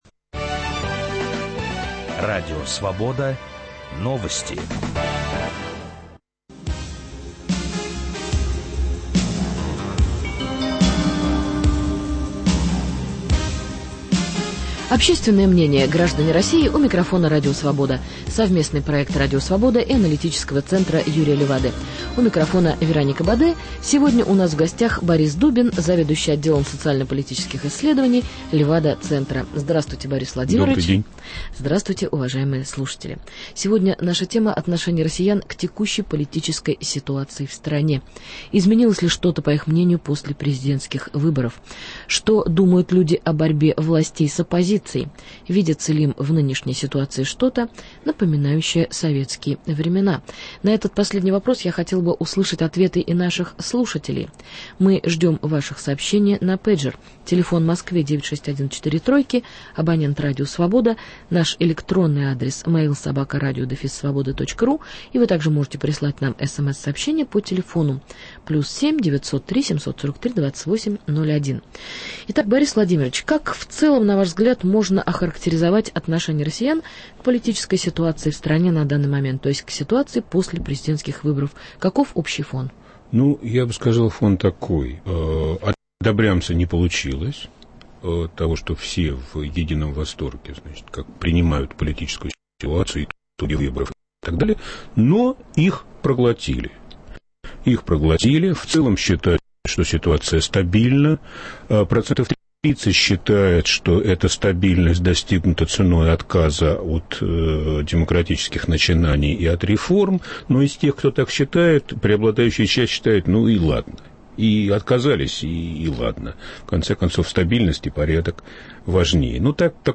Самые свежие социологические исследования на политические, экономические, социальные и культурные темы, представленные учеными Левада-центра. Живые голоса россиян: опросы, проведенные Радио Свобода на улицах российских городов, и комментарии специалистов.